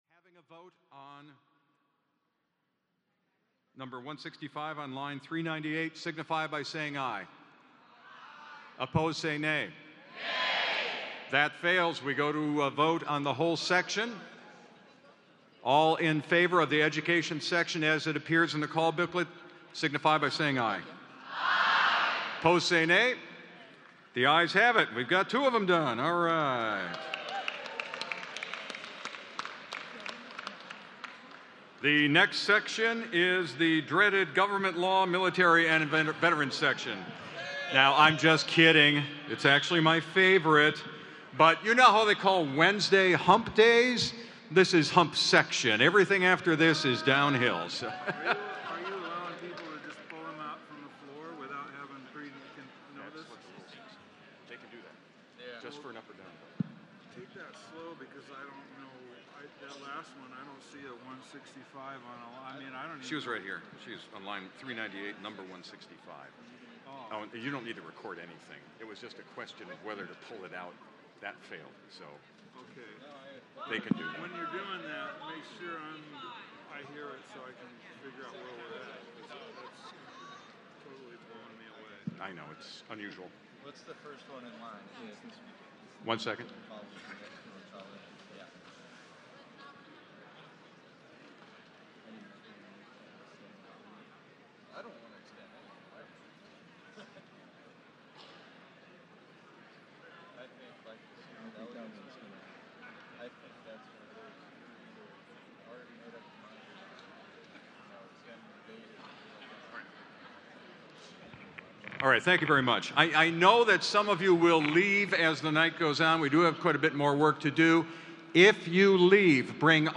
Six Iowa Democrats at the state convention each were given a chance to speak for a minute about the issue before a vote was taken.